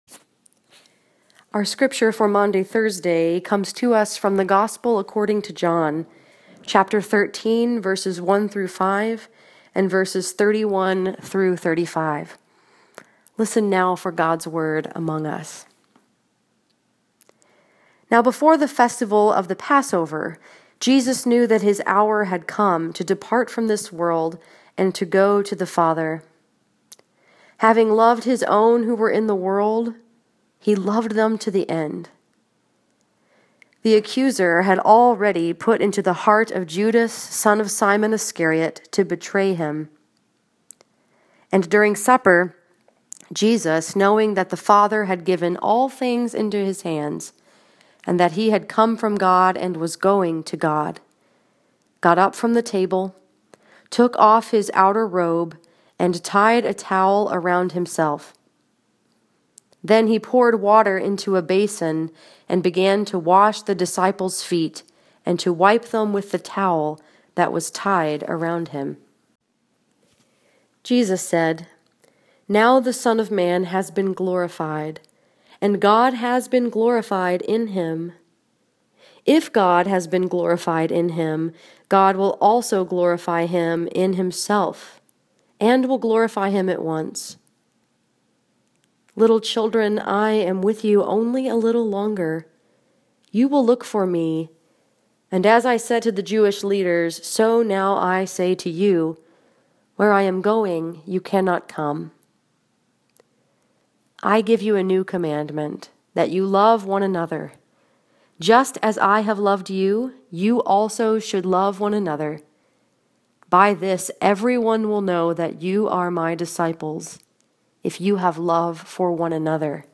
Today is Maundy Thursday in the Holy Week tradition.
maundy-thursday.m4a